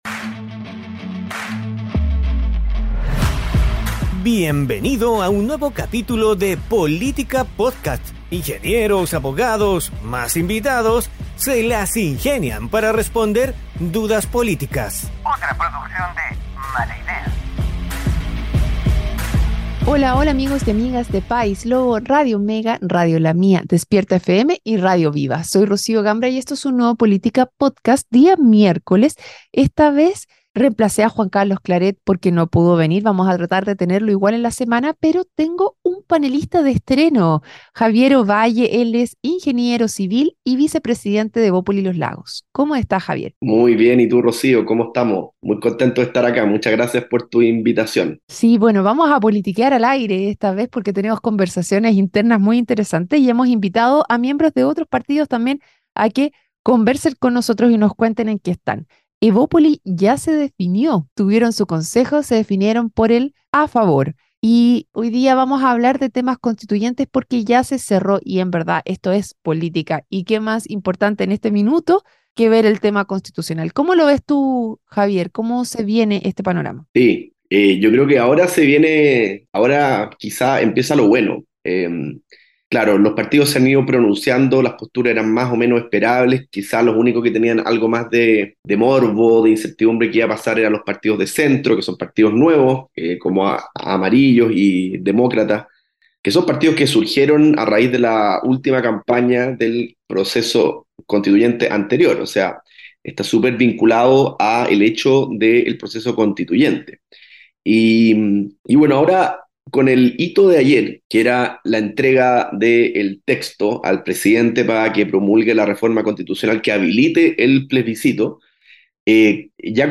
Con discurso del Presidente de la República, Gabriel Boric, y de la presidenta del Consejo Constitucional, Beatriz Hevia, se realizó la ceremonia de cierre del proceso constitucional 2023.